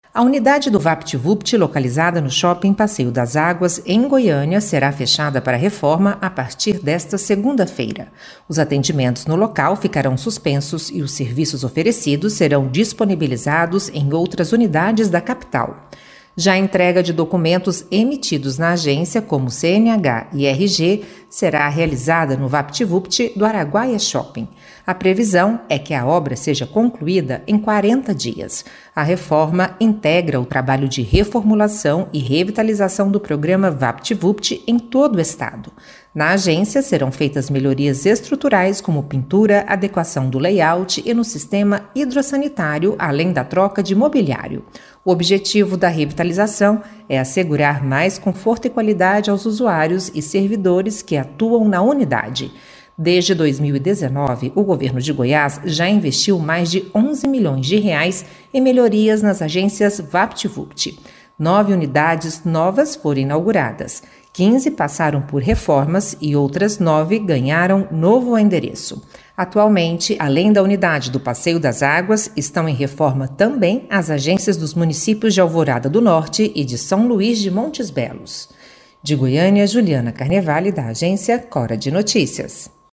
Rádio
Repórter